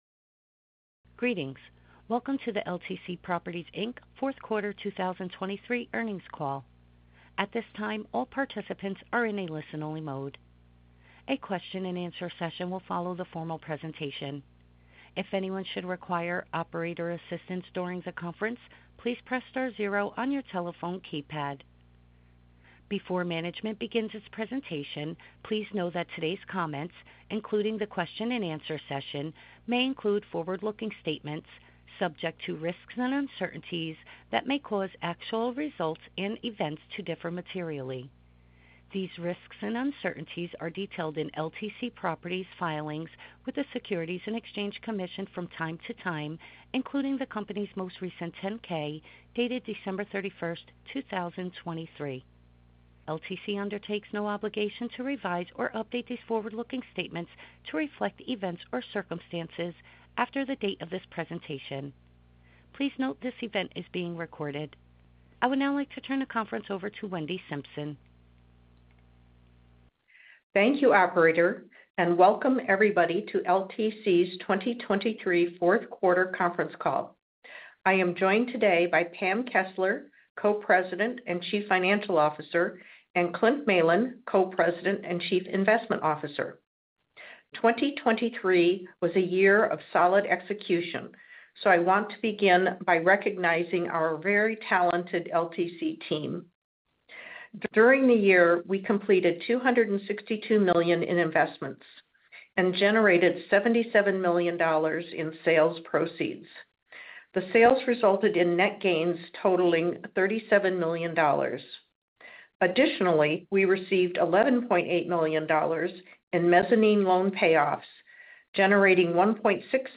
Earnings Webcast FY 2023 Audio